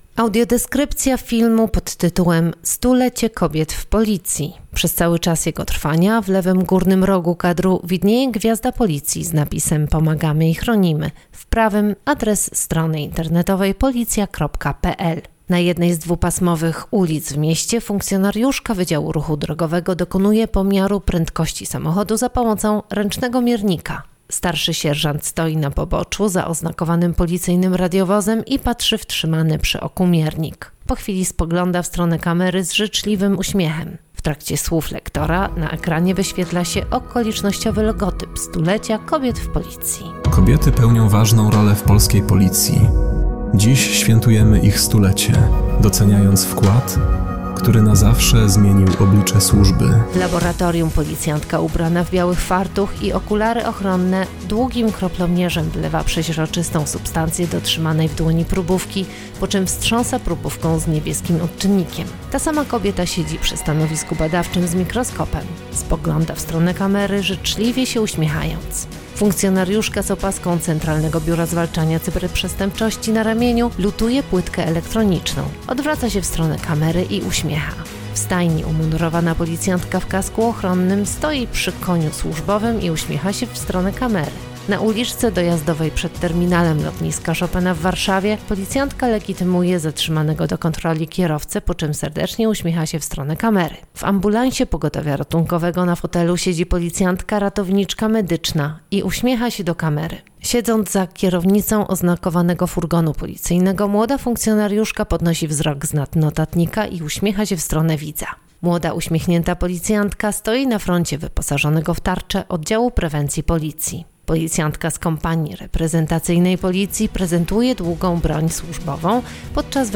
Audiodeskrypcja do filmu
Nagranie audio Audiodeskrypcja do filmu: 100 lat kobiet w Policji